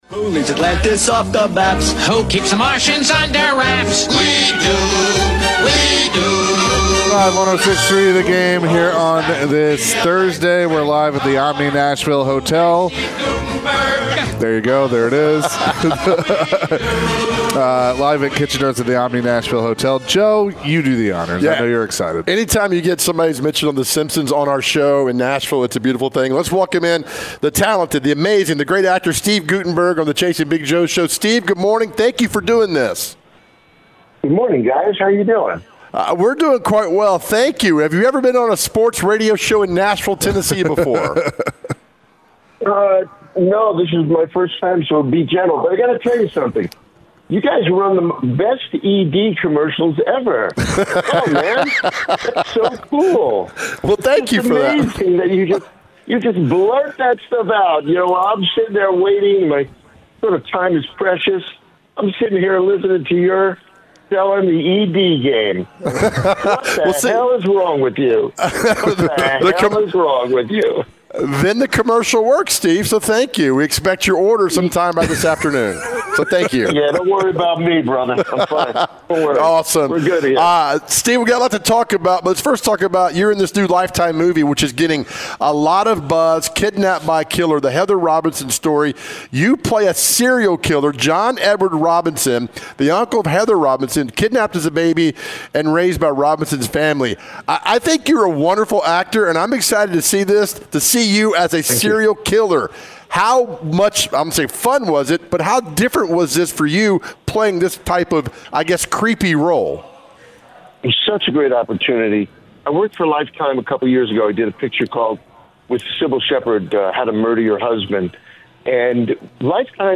The guys chatted with Steve Guttenberg talking about his new movie that is coming out. Plus Steve went down memory lane and shared some laughs with the guys talking about his movies.